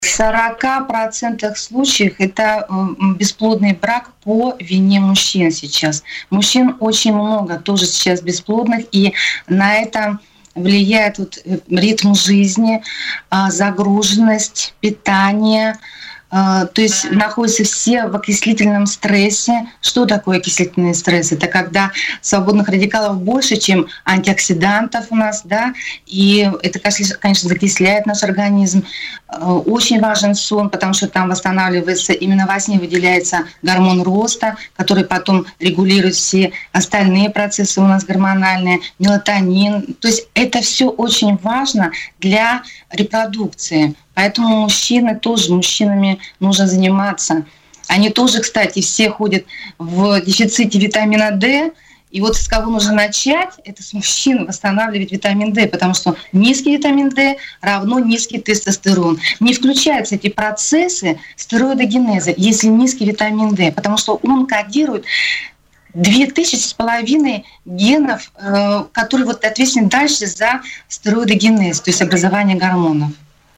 28 апреля 2020, 14:00 Radio Baltkom